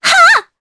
Laias-Vox_Attack3_jp.wav